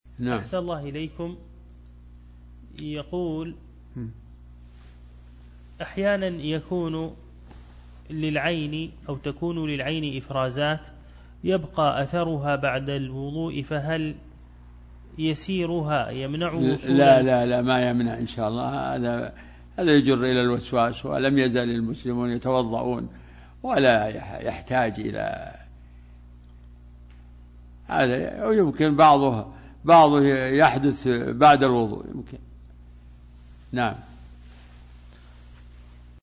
فتاوى الدروس